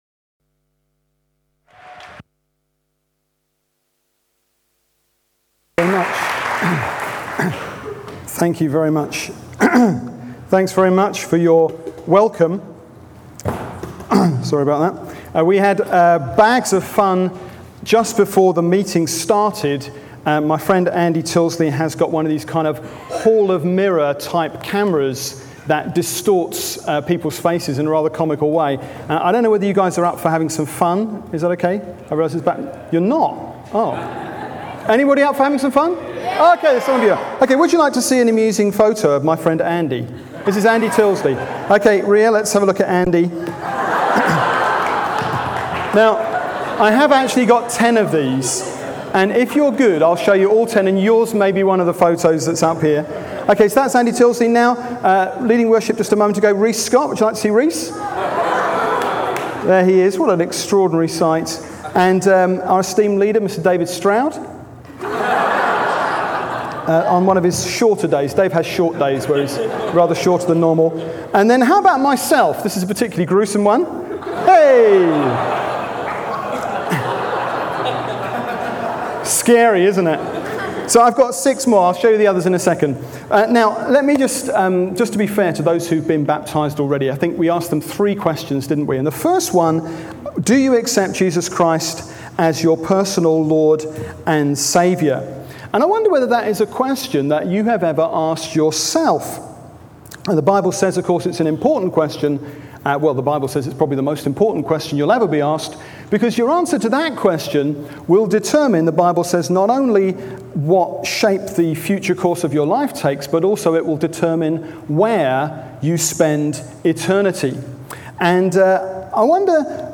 Matthews Gospel. Preaching from ChristChurch London’s Sunday service. A Baptism service.